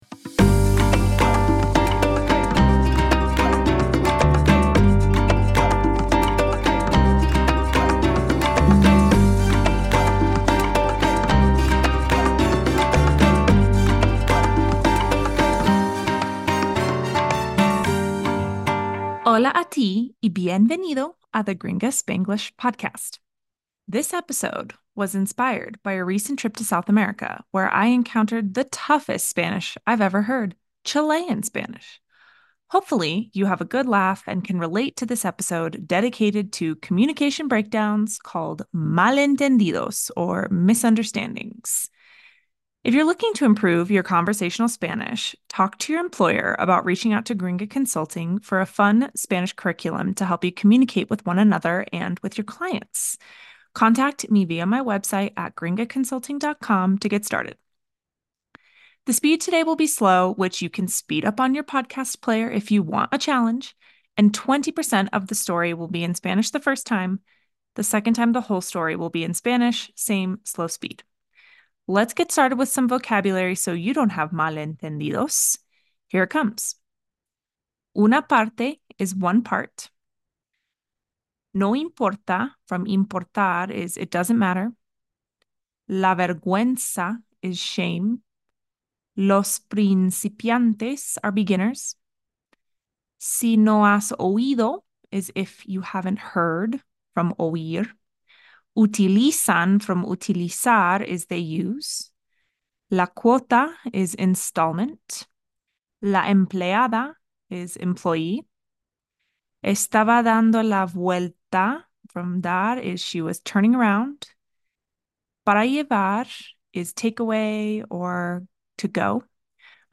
S03 E16 - Malentendidos - Slow Speed - 20% Spanish